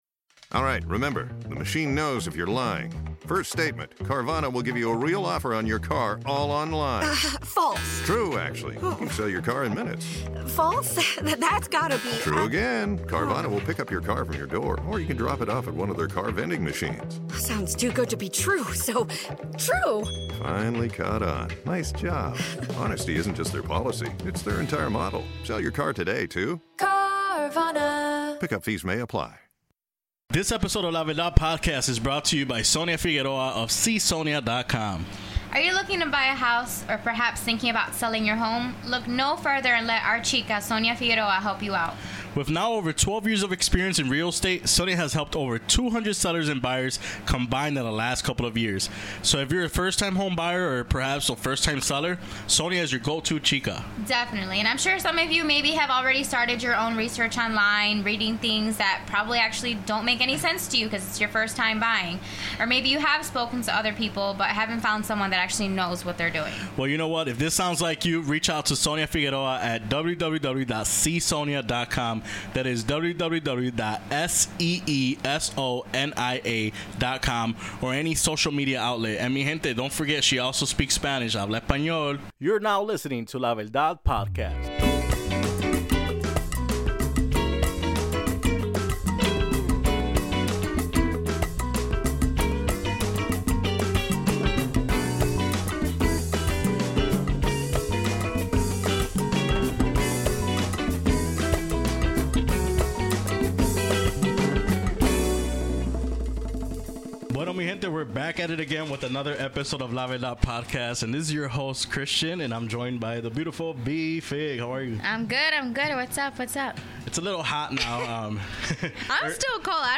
Conversation includes: